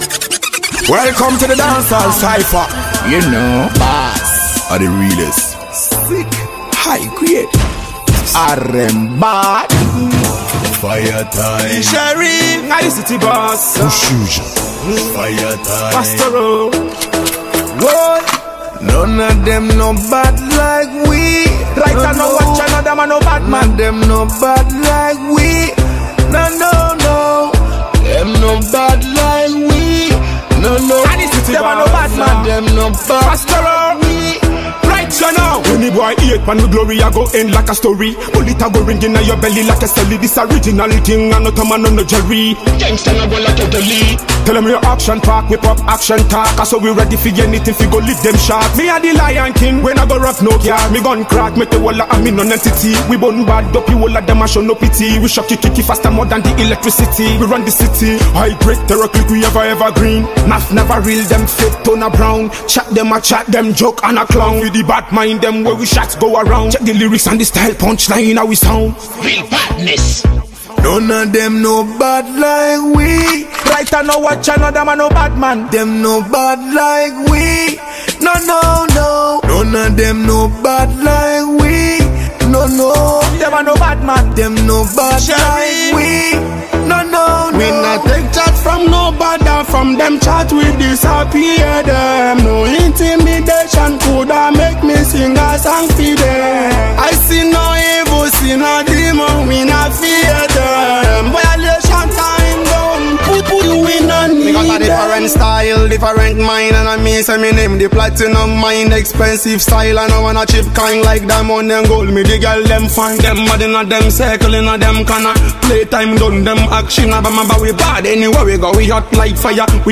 Reggae/Dancehall
New dancehall hit taking over the street.